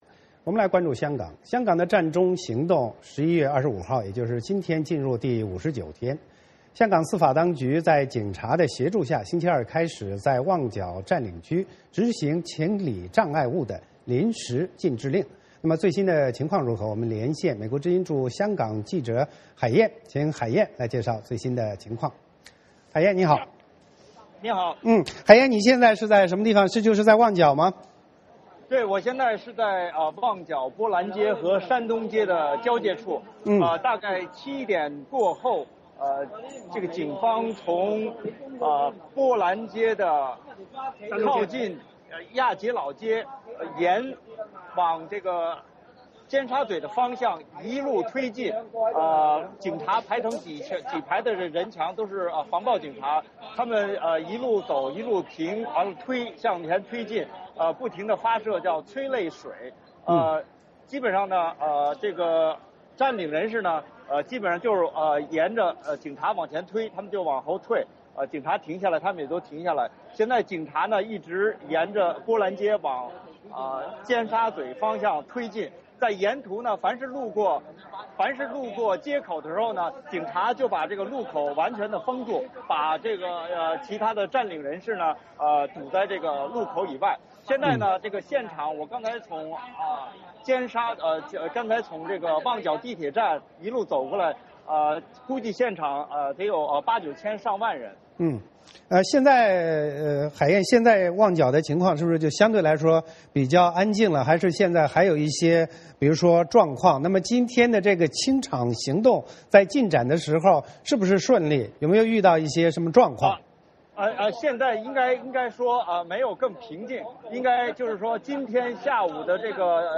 VOA连线：香港当局清理旺角占领区